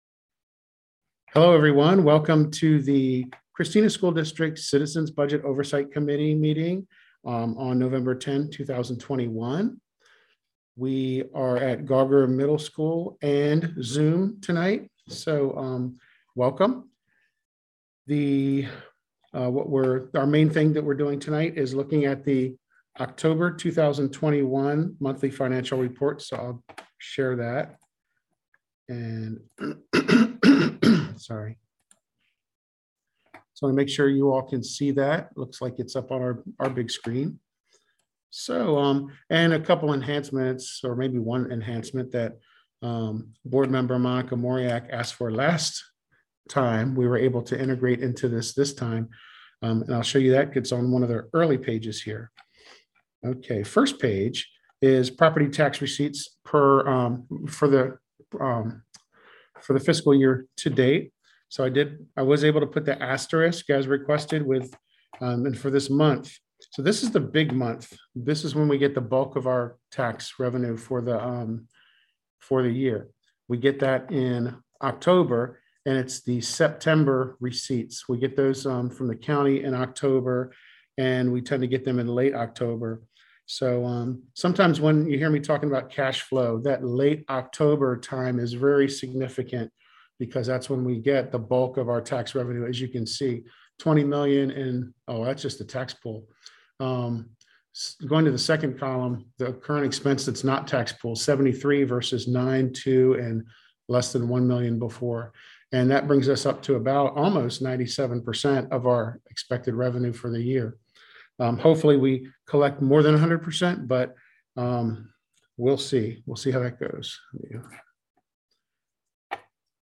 Presentation (audio only)